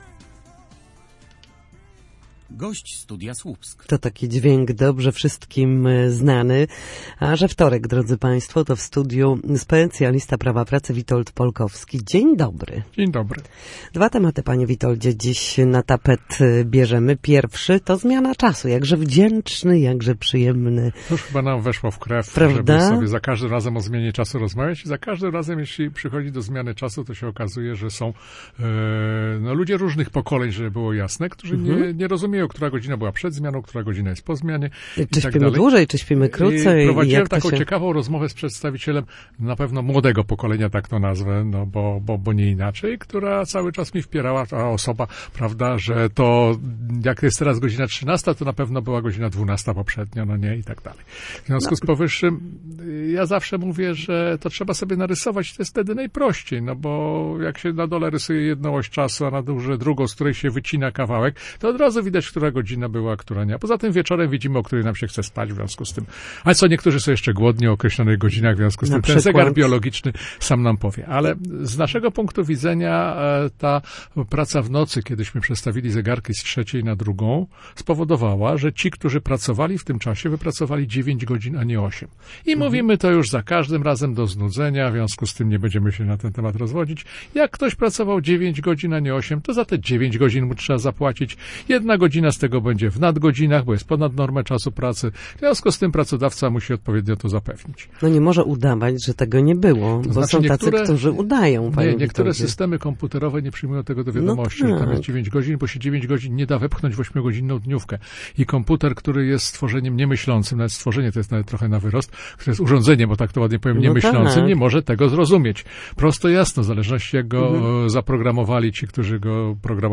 W każdy wtorek po godzinie 13:00 na antenie Studia Słupsk przybliżamy zagadnienia dotyczące prawa pracy.